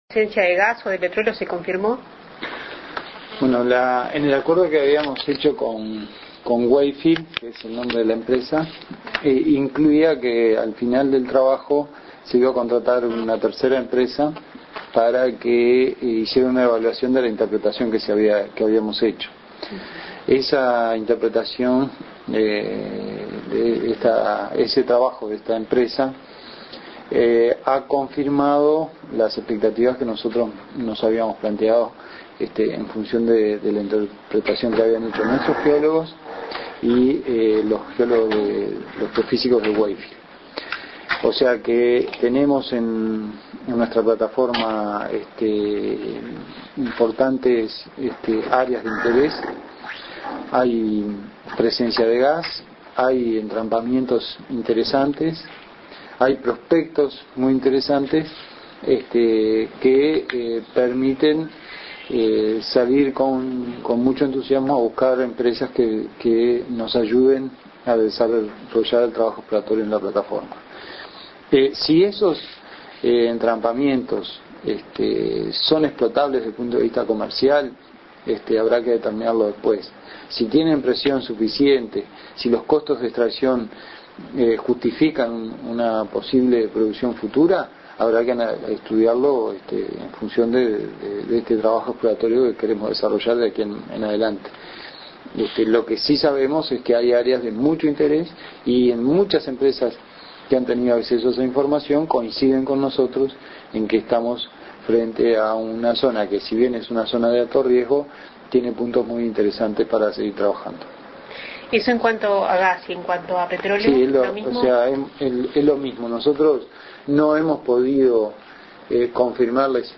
Entrevista a Ra�l Sendic